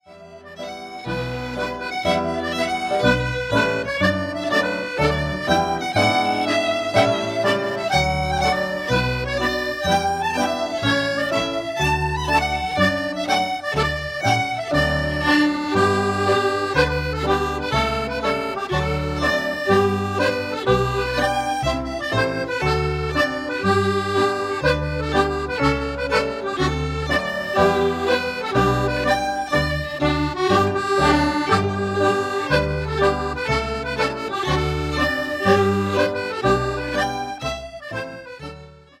accordion
fiddle
piano
bass